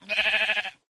Minecraft / mob / sheep / say2.ogg